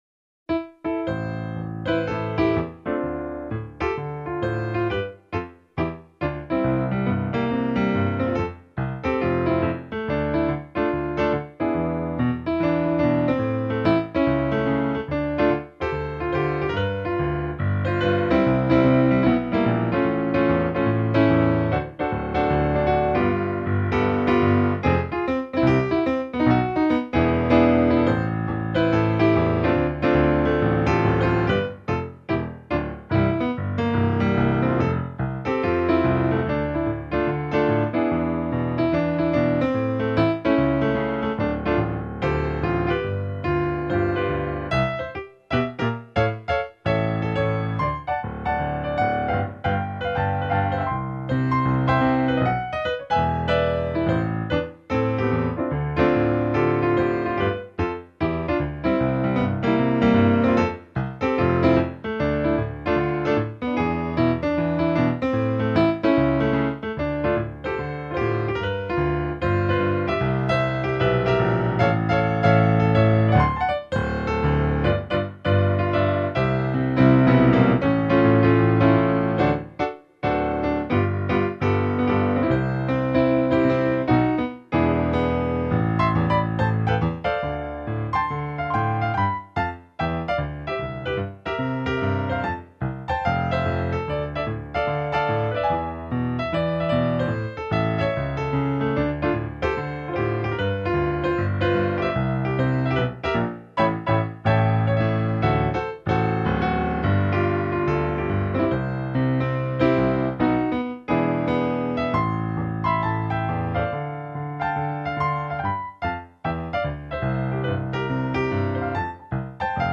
Just piano (no vocals) sample tracks